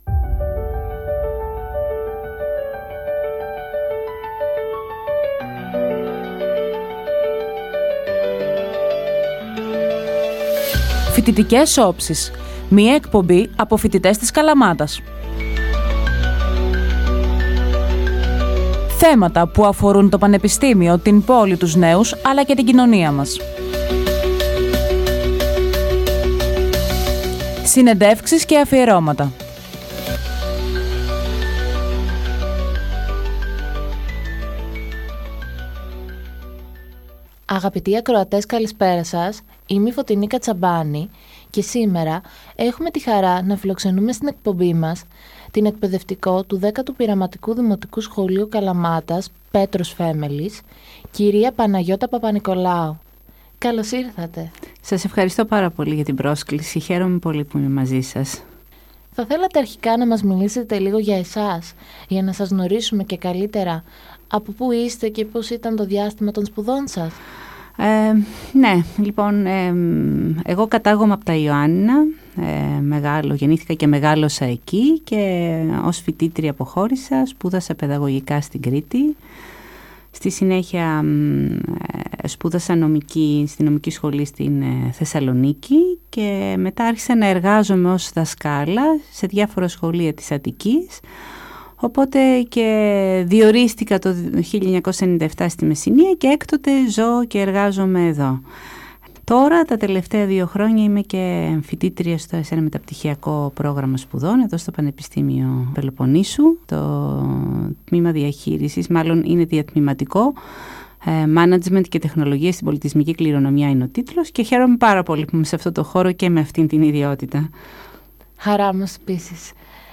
Μια όμορφη και εμπνευσμένη συζήτηση για το όραμά της στην εκπαίδευση, τη δημιουργικότητα στην τάξη και τη δύναμη της γνώσης που καλλιεργεί ψυχές.